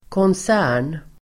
Ladda ner uttalet
Uttal: [kåns'ä:r_n]